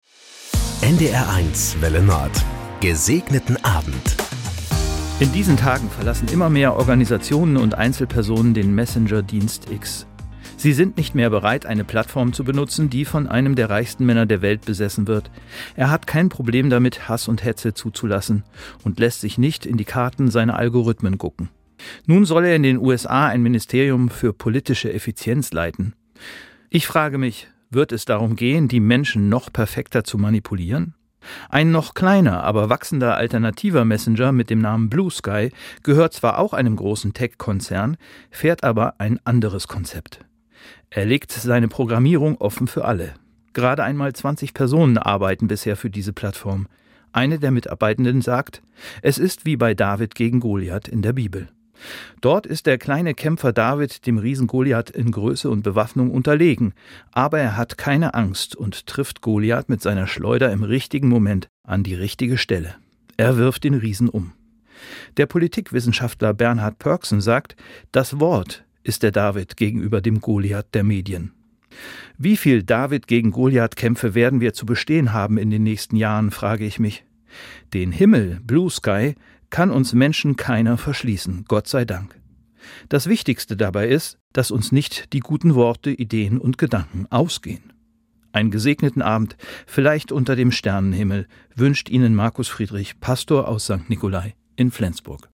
Das gute Wort zum Feierabend auf NDR 1 Welle Nord mit den Wünschen für einen "Gesegneten Abend".
Täglich um 19.04 Uhr begleiten wir Sie mit einer Andacht in den Abend - ermutigend, persönlich, aktuell, politisch, tröstend.